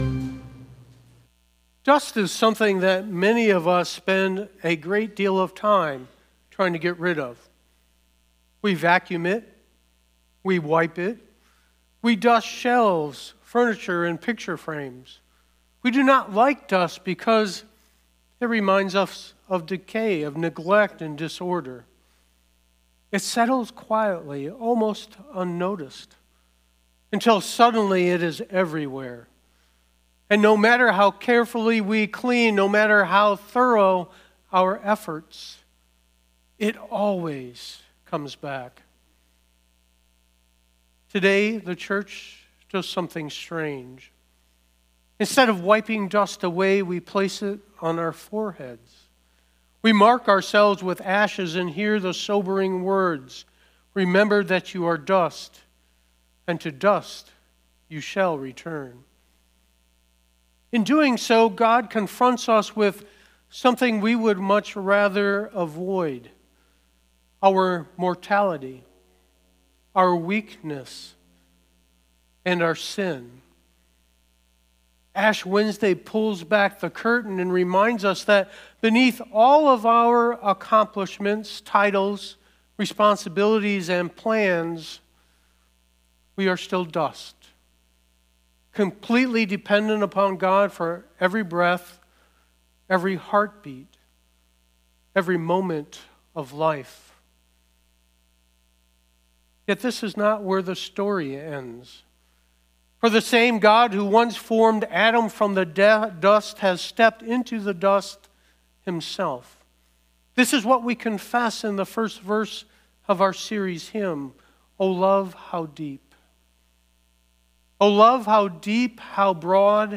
Sermons – Page 8
Ash-Wednesday-2026.mp3